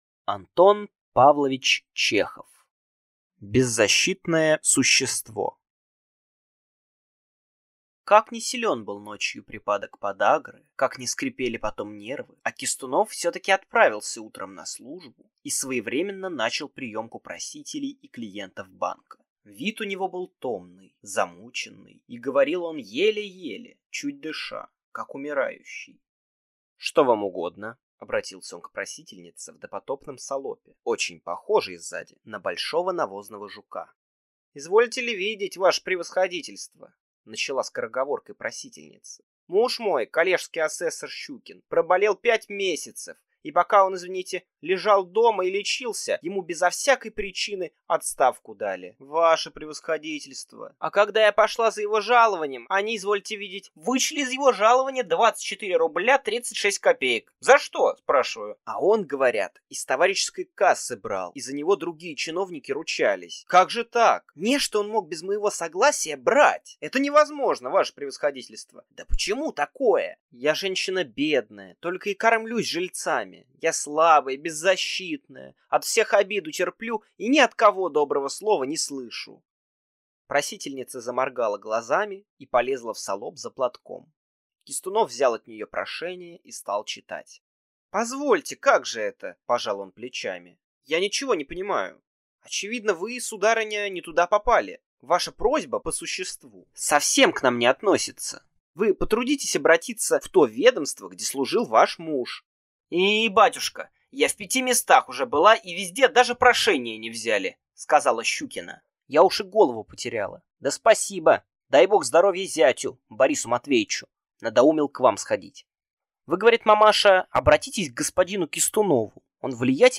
Аудиокнига Беззащитное существо | Библиотека аудиокниг